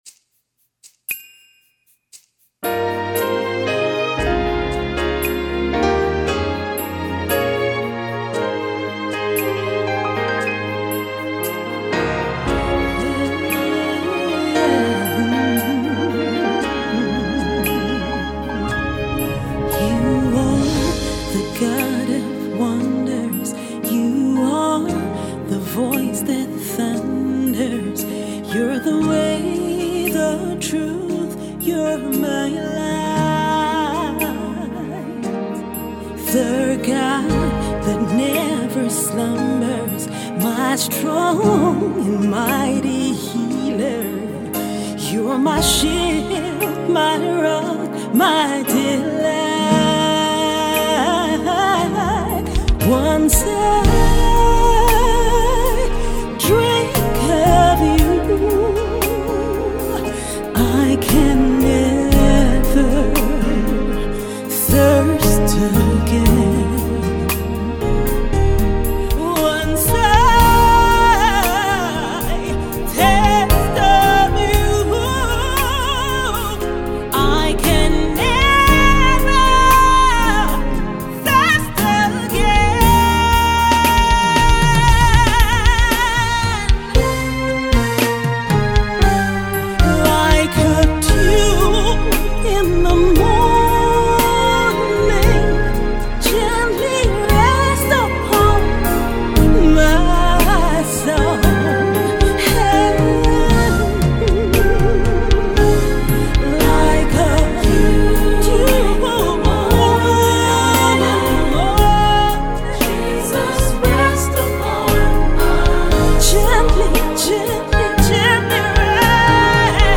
Abuja Gospel Singer